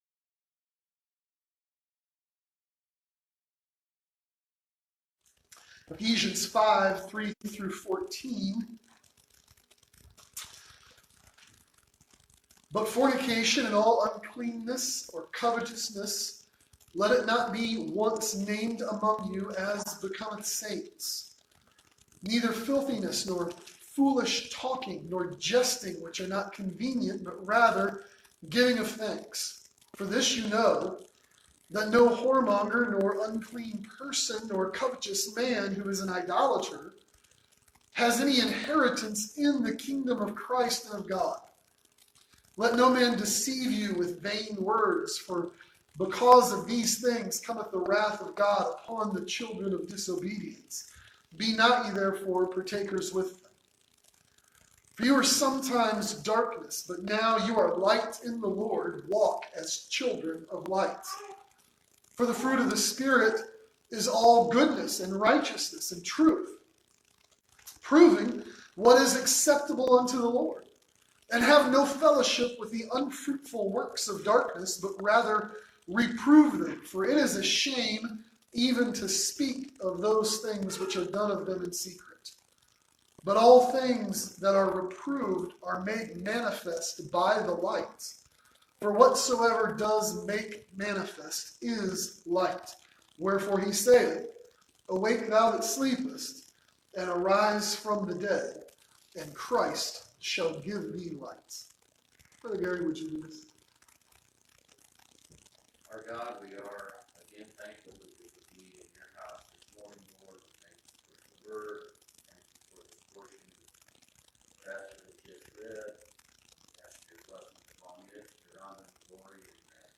Beverly Manor Baptist Church